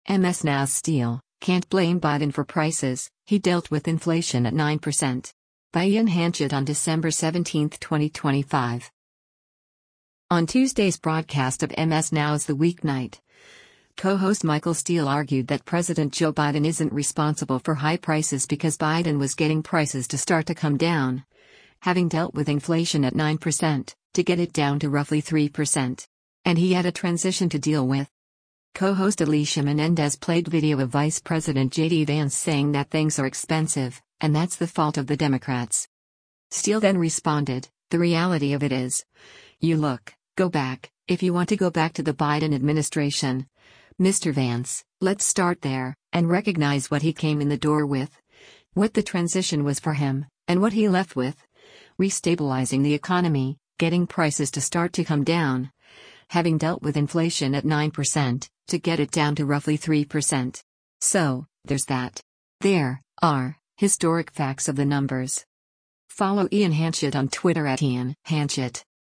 On Tuesday’s broadcast of MS NOW’s “The Weeknight,” co-host Michael Steele argued that President Joe Biden isn’t responsible for high prices because Biden was “getting prices to start to come down, having dealt with inflation at 9%, to get it down to roughly 3%.”
Co-host Alicia Menendez played video of Vice President JD Vance saying that things are expensive, and that’s the fault of the Democrats.